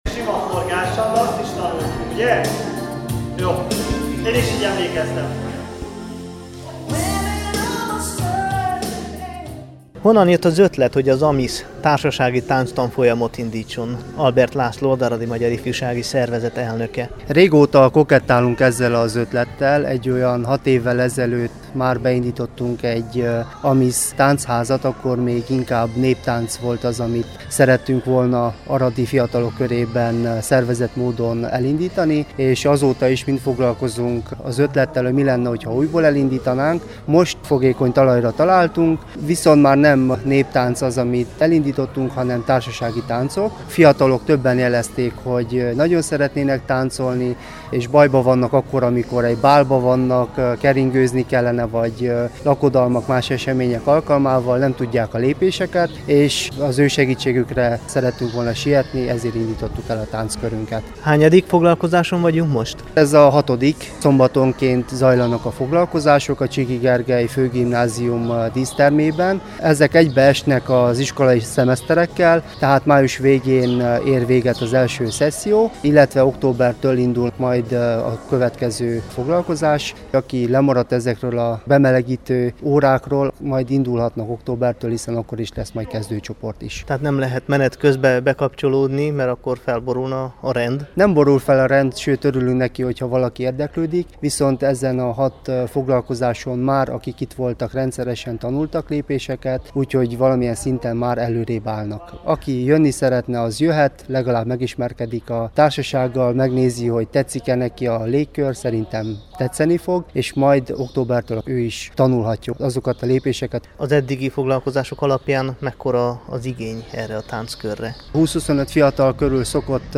Hallgassa meg a Temesvári Rádió ifjúsági műsora számára készült riportot!